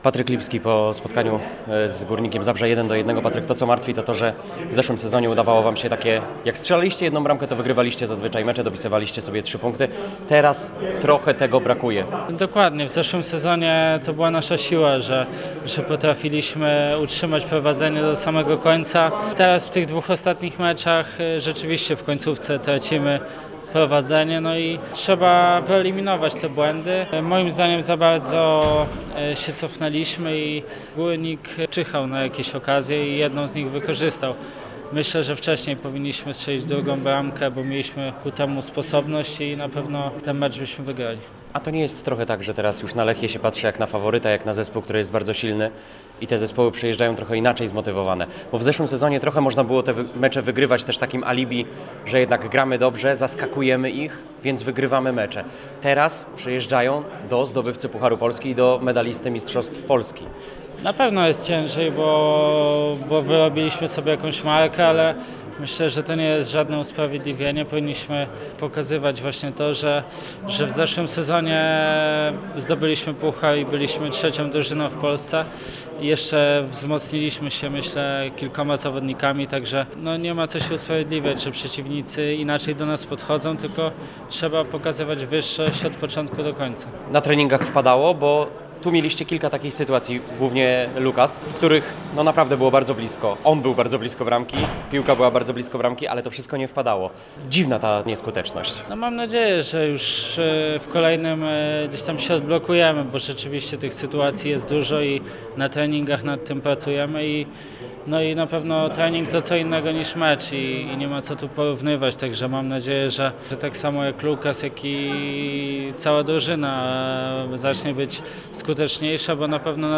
Ale to nie jest usprawiedliwienie” [ROZMOWA]